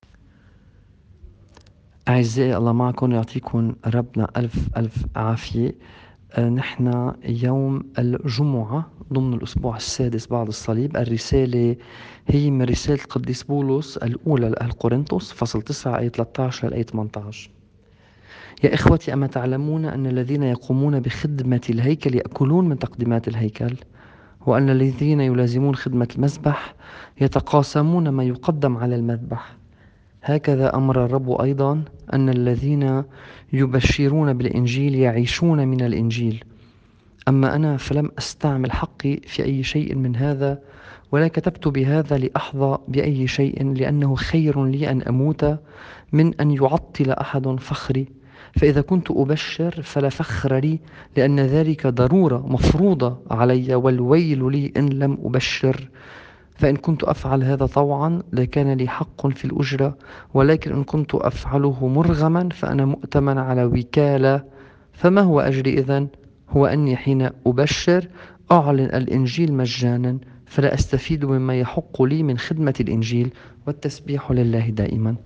الرسالة بحسب التقويم الماروني :